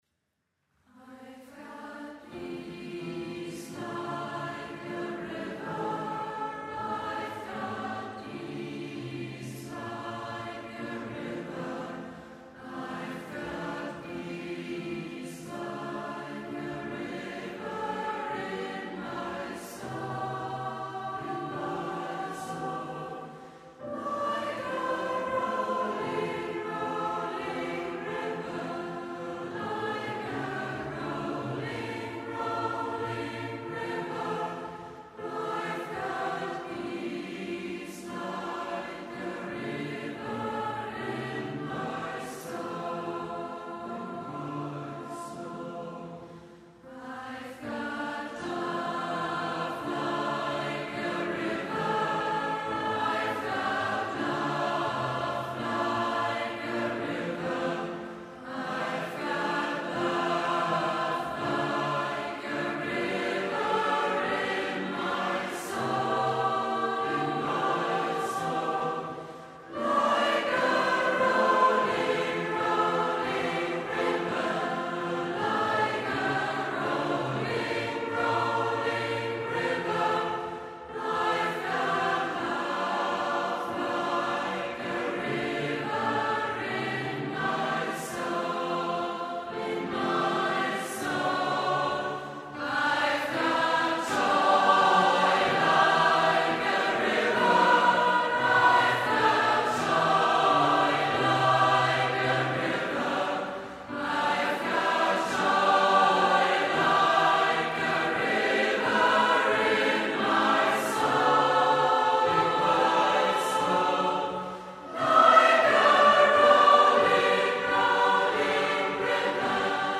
Musik-Workshop & CD-Produktion 2011
Aktuelle Geistliche Chormusik - Gospels, Spirituals